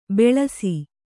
♪ beḷasi